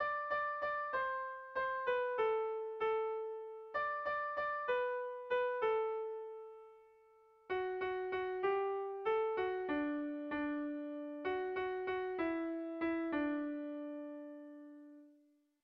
Erlijiozkoa
Lauko txikia (hg) / Bi puntuko txikia (ip)
AB